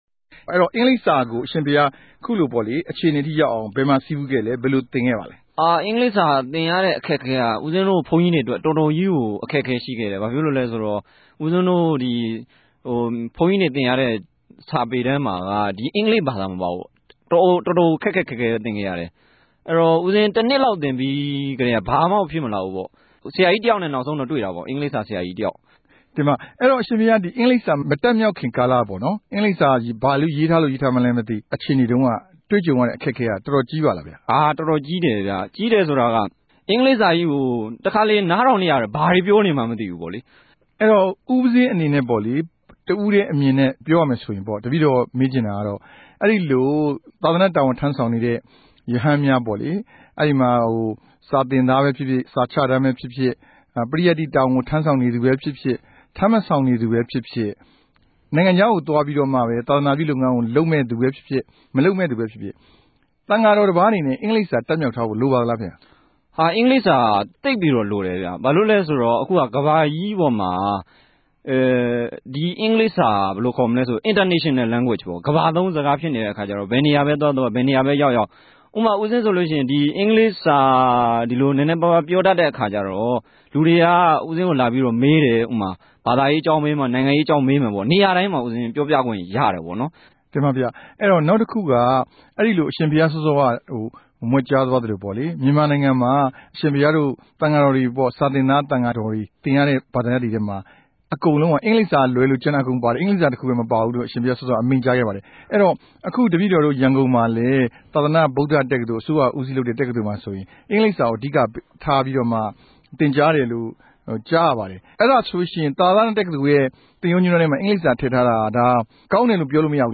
ူမန်မာိံိုင်ငံမြာ ရဟန်းသံဃာမဵားကို စစ်အစိုးရက အကြက်ကဵကဵ ဖိံြိပ်ထားေုကာင်း ဂဵာမနီံိုင်ငံမြ ူမန်မာ ရဟန်းတပၝးက RFA ကို ေူပာုကားခဲ့ပၝတယ်။